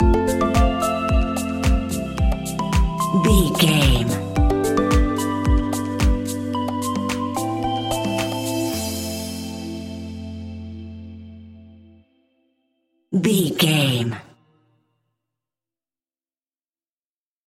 Aeolian/Minor
groovy
peaceful
tranquil
smooth
drum machine
synthesiser
house
electro house
instrumentals
synth leads
synth bass